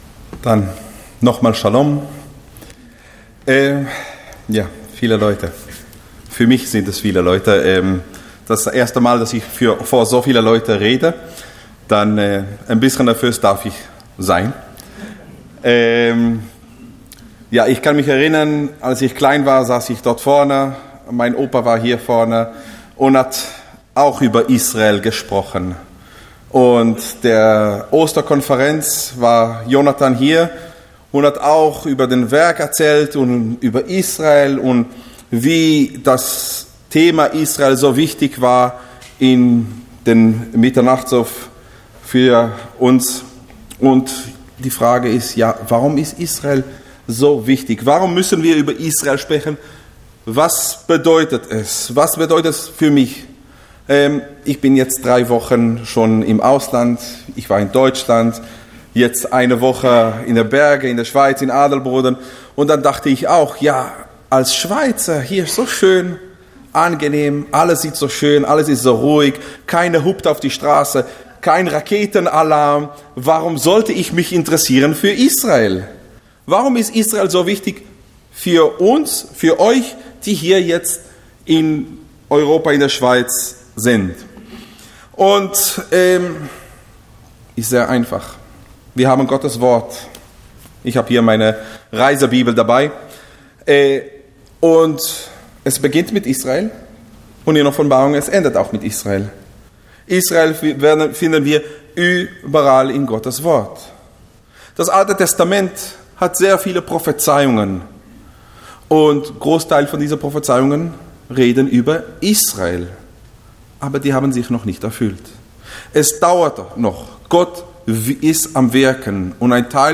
Botschaft Zionshalle